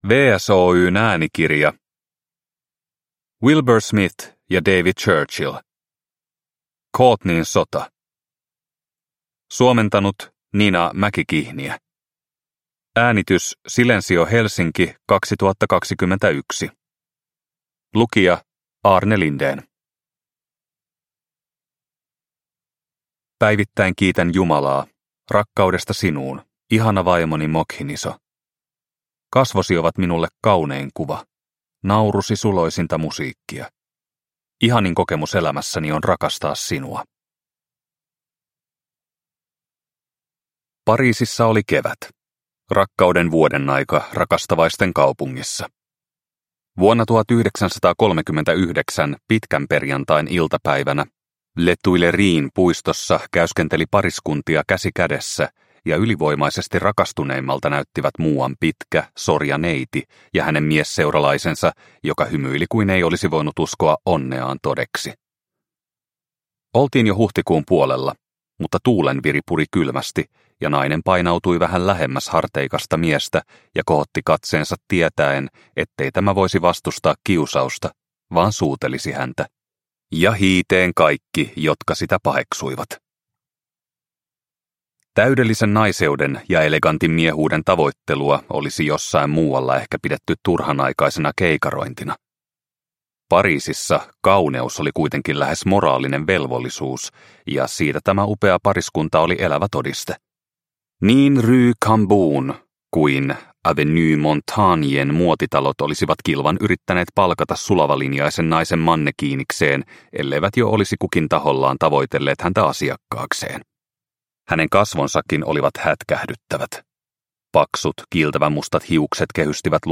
Courtneyn sota – Ljudbok – Laddas ner